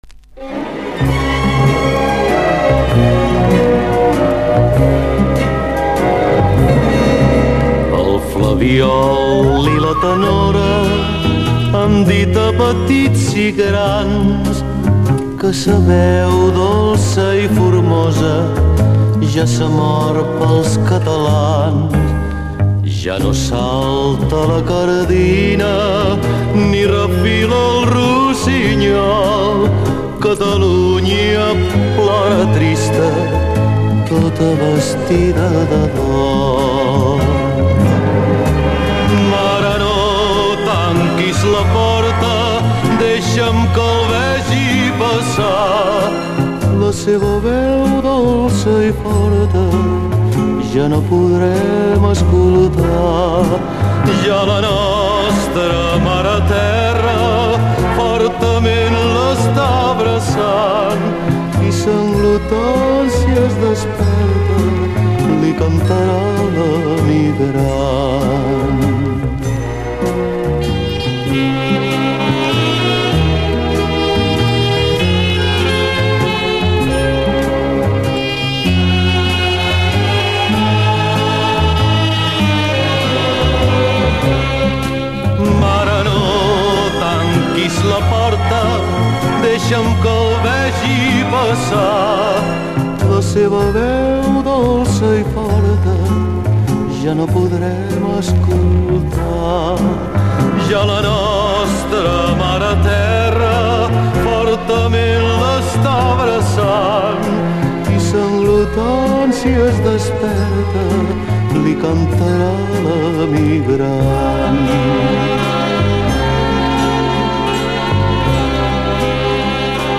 был известнейшим испанским крунером